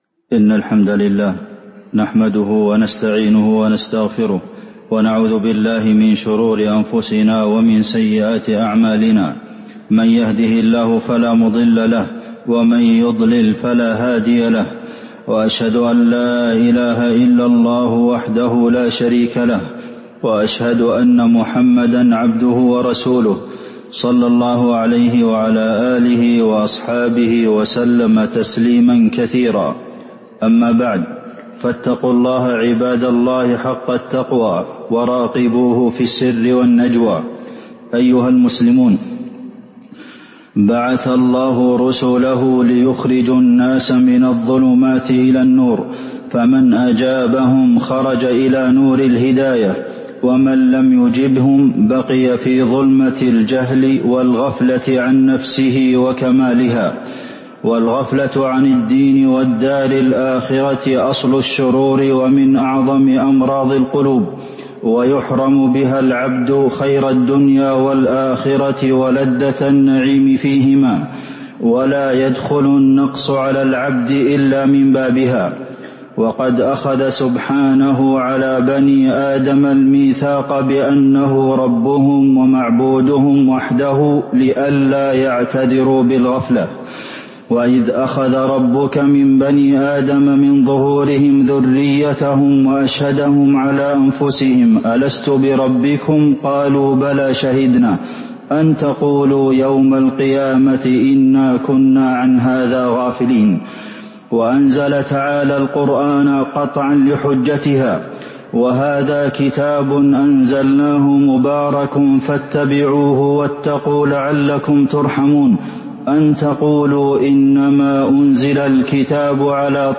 تاريخ النشر ٢٠ محرم ١٤٤٦ هـ المكان: المسجد النبوي الشيخ: فضيلة الشيخ د. عبدالمحسن بن محمد القاسم فضيلة الشيخ د. عبدالمحسن بن محمد القاسم التحذير من الغفلة